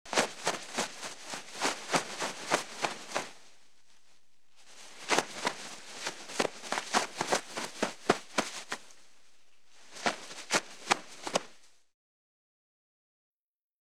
Toilet Paper from Roll Pulling Off Sound
household